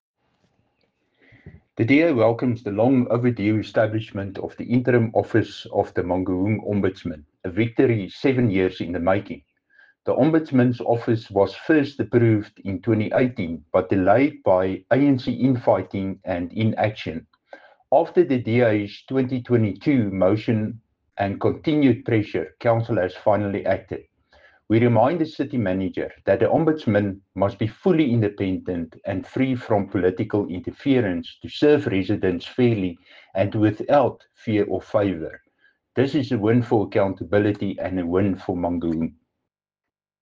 English and Afrikaans soundbites by Cllr Dirk Kotze and